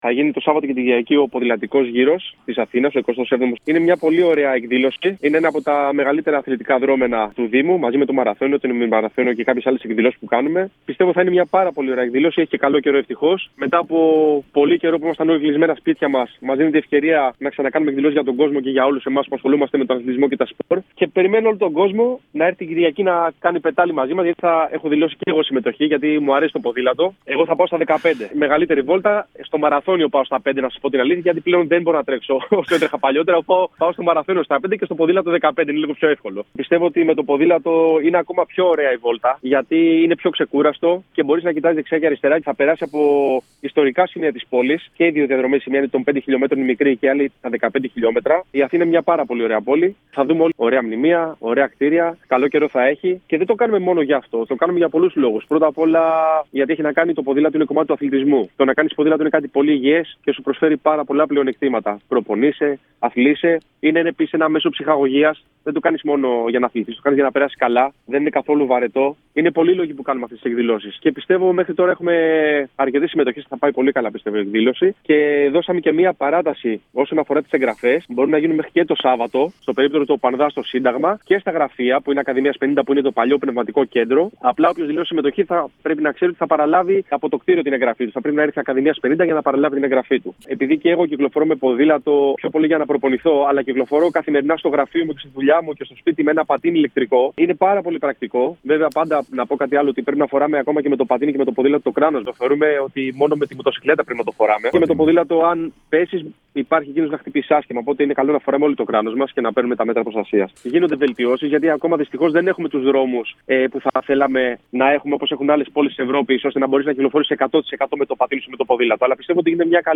Στην ΕΡΑΣΠΟΡ και την εκπομπή «επί παντός επιστητού» μίλησε ο αντιπρόεδρος του ΟΠΑΝΔΑ και παλιά δόξα του Παναθηναϊκού αλλά και της εθνικής Ελλάδας.